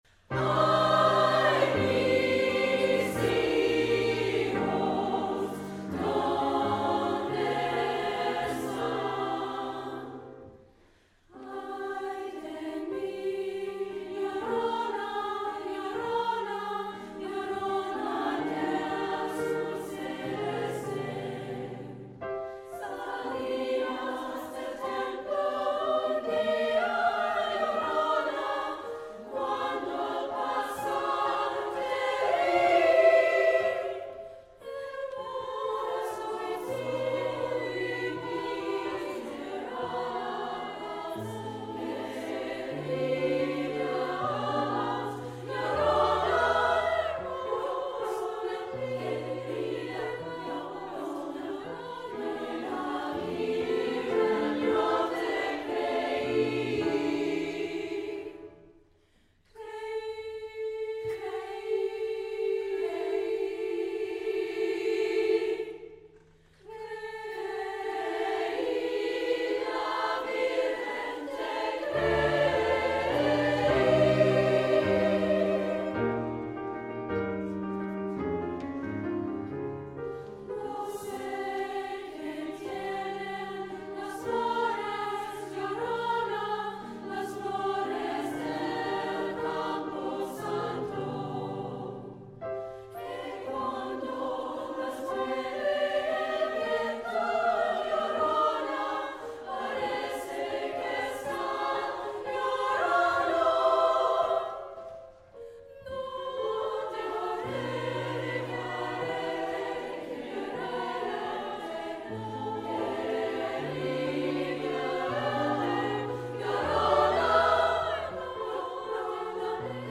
SSA + Piano
SSA, Piano